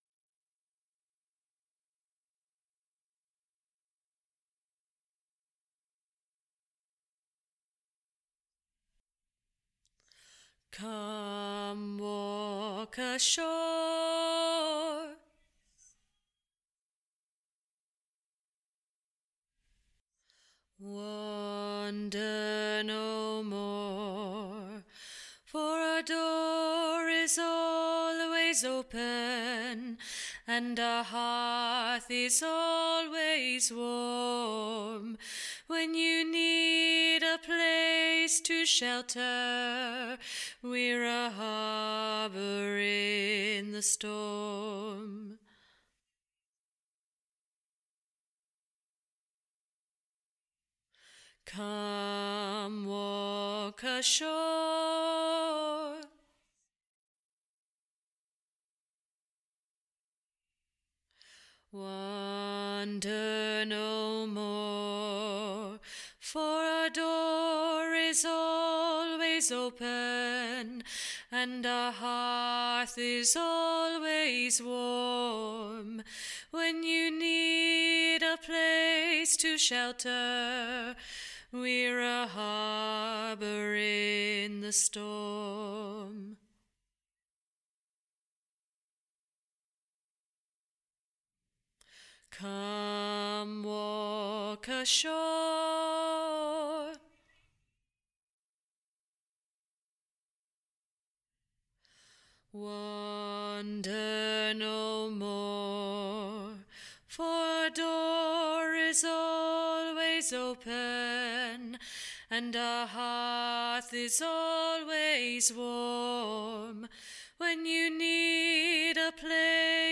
Harbour Tenor NO UNISON